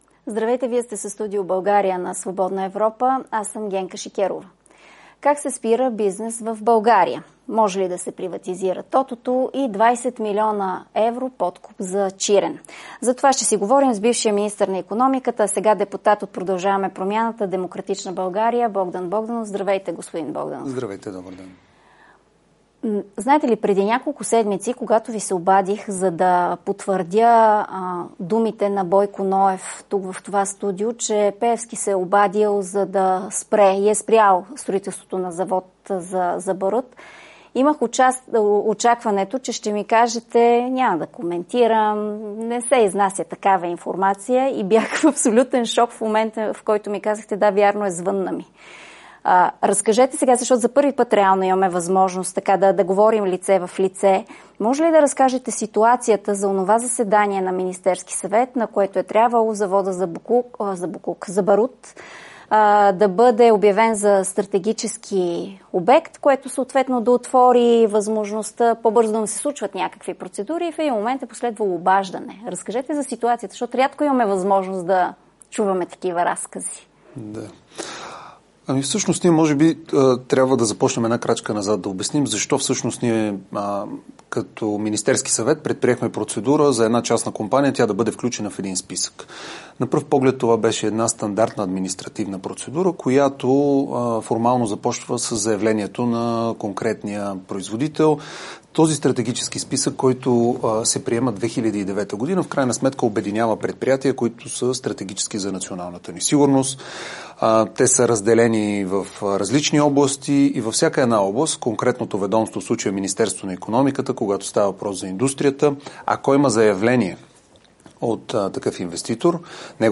По тези въпроси Генка Шикерова разговаря с бившия министър на икономиката, а сега депутат от ПП-ДБ, Богдан Богданов.